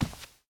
cloth2.ogg